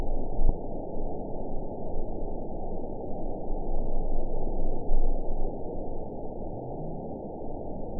event 911208 date 02/18/22 time 02:20:52 GMT (3 years, 9 months ago) score 9.12 location TSS-AB01 detected by nrw target species NRW annotations +NRW Spectrogram: Frequency (kHz) vs. Time (s) audio not available .wav